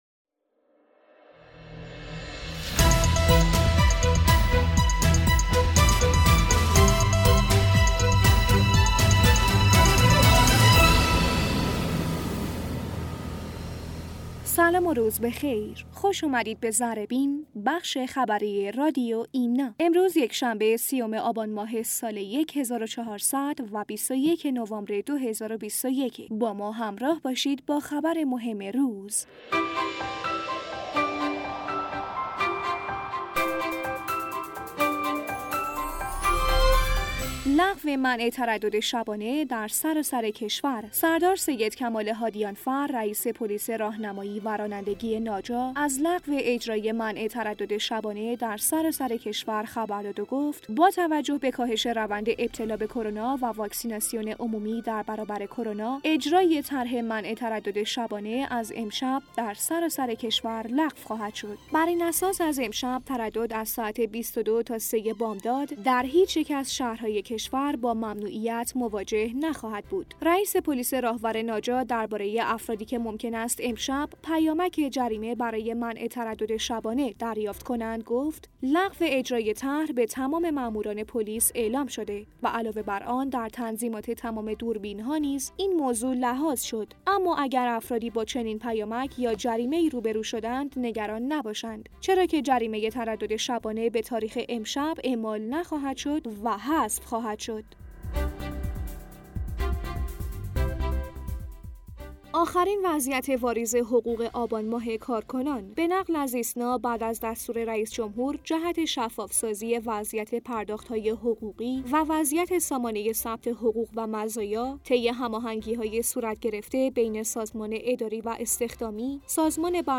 در بسته خبری امروز رادیو ایمنا از جزییات لغو منع تردد شبانه از شب گذشته ۲۹ آبان ماه و آخرین خبر از واریز حقوق کارکنان در آبان می شنویم.